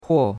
huo4.mp3